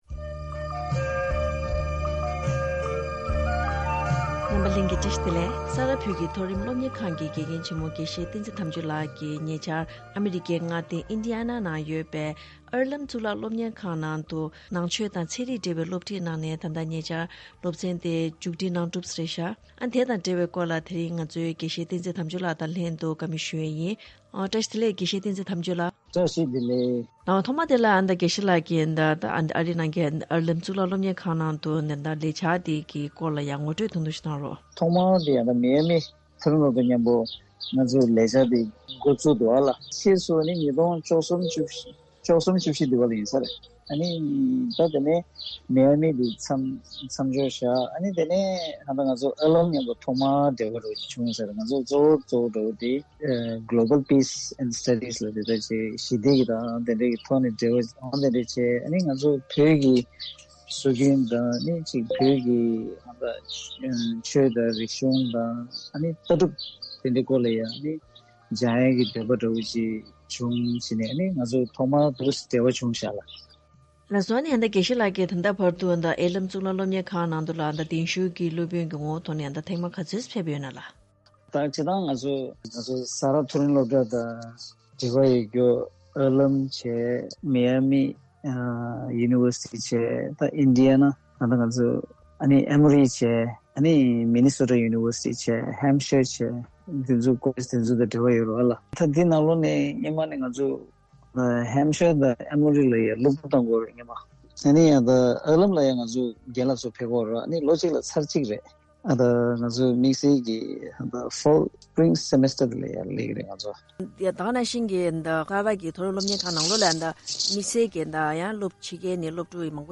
བཀའ་དྲི་ཞུས་པ་ཞིག་གསན་རོགས་གནང་།